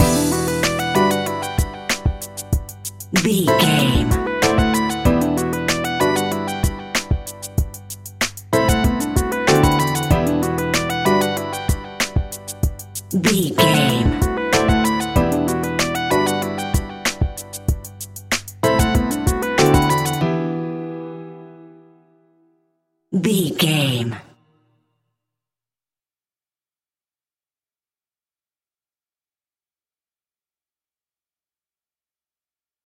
Aeolian/Minor
instrumentals
chilled
laid back
groove
hip hop drums
hip hop synths
piano
hip hop pads